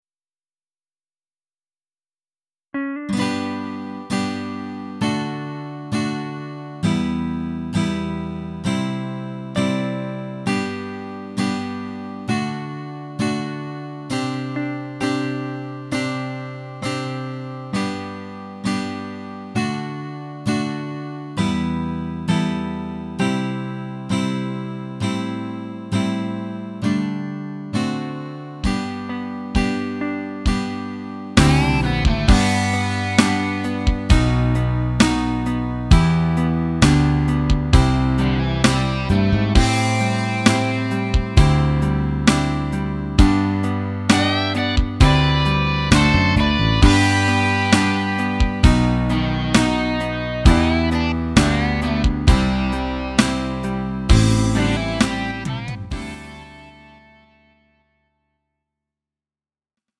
음정 -1키 3:56
장르 가요 구분 Lite MR
Lite MR은 저렴한 가격에 간단한 연습이나 취미용으로 활용할 수 있는 가벼운 반주입니다.